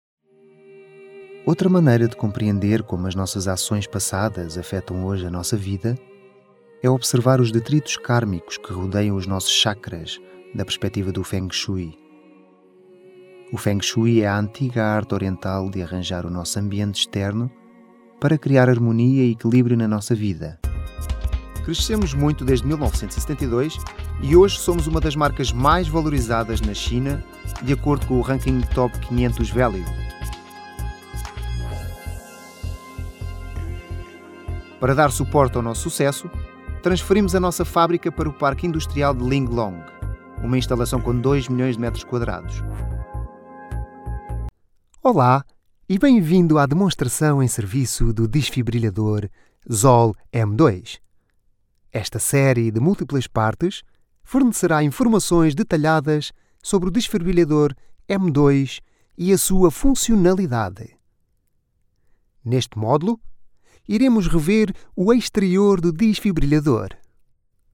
Portuguese, Male, 30s-40s